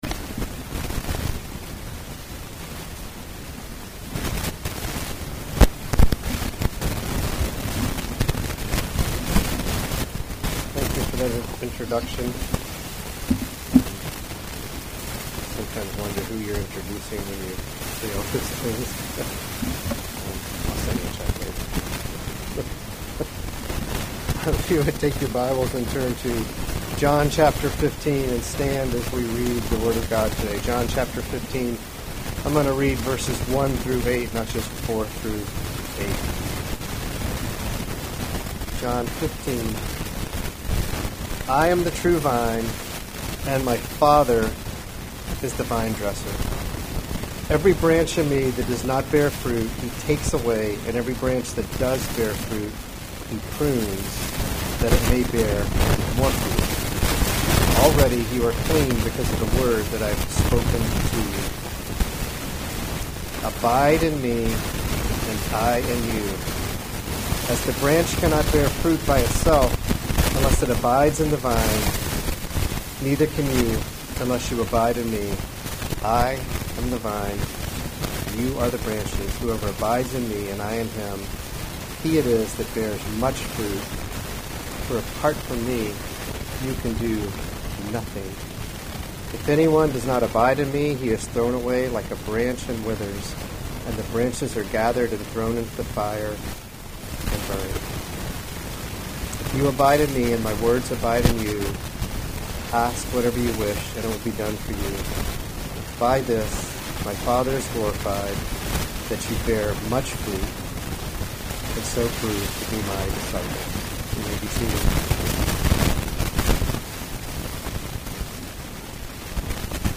July 25, 2021 Guest Speaker Sermons series Weekly Sunday Service Save/Download this sermon John 15:4-8 Other sermons from John 4 Abide in me, and I in you.